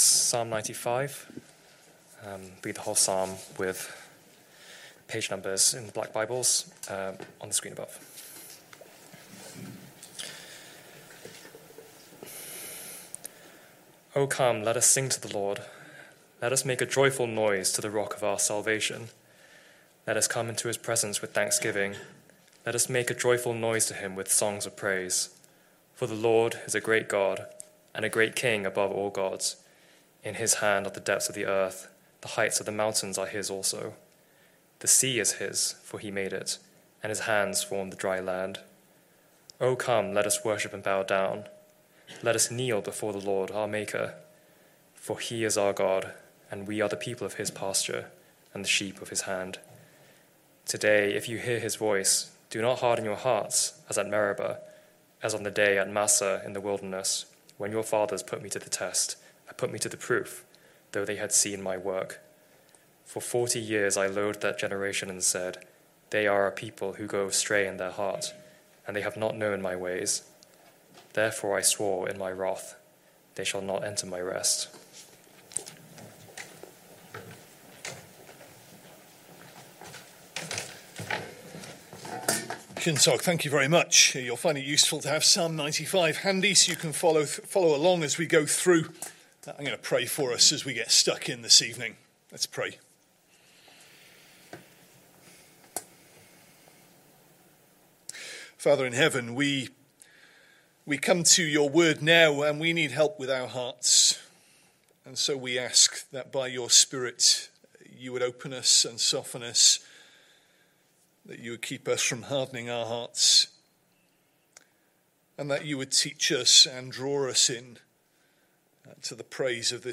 Sunday PM Service Sunday 2nd November 2025 Speaker